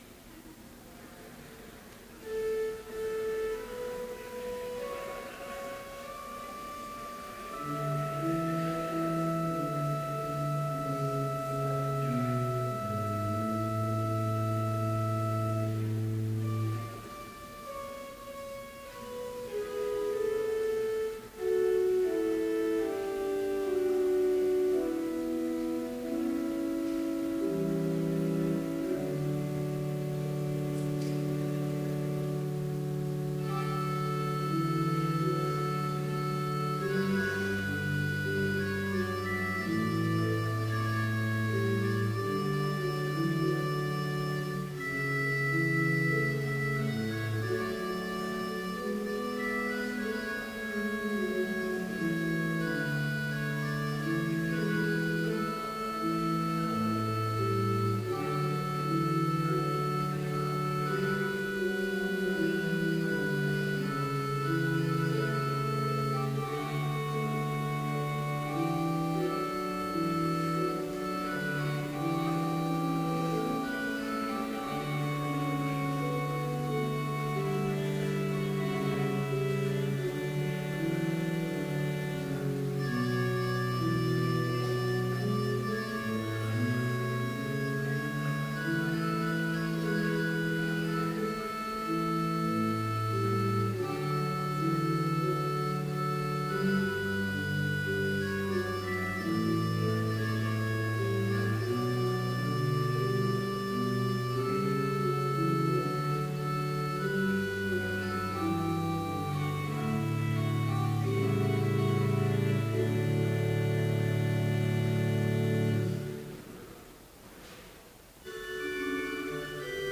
Complete service audio for Chapel - May 1, 2014